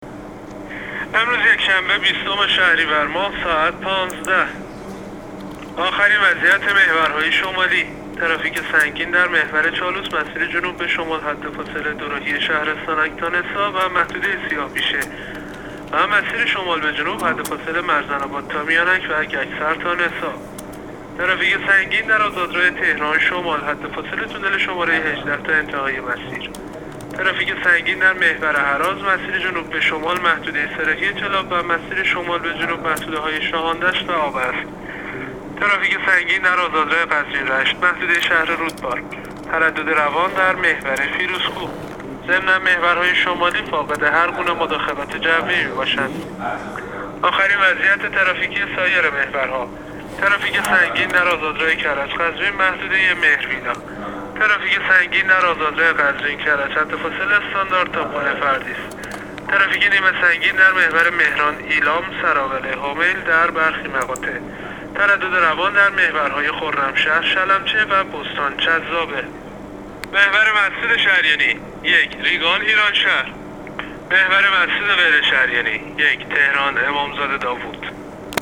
گزارش رادیو اینترنتی از آخرین وضعیت ترافیکی جاده‌ها تا ساعت ۱۵ بیستم شهریور؛